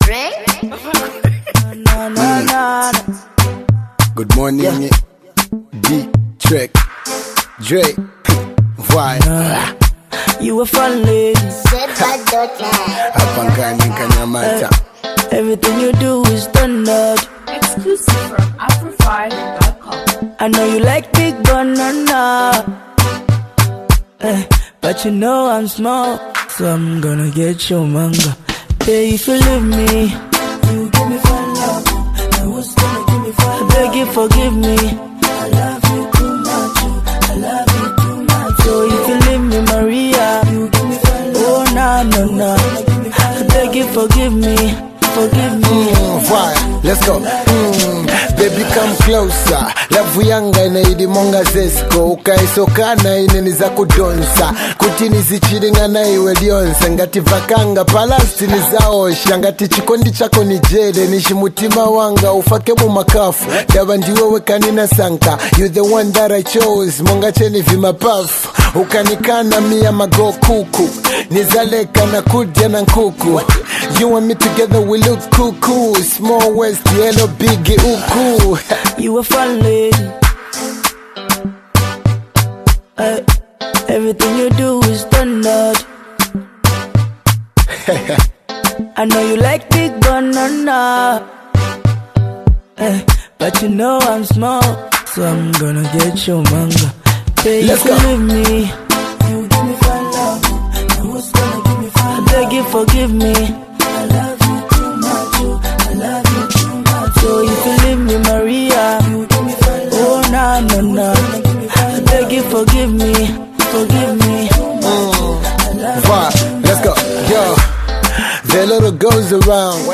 Category: Zambian Music